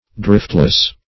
Driftless \Drift"less\, a. Having no drift or direction; without aim; purposeless.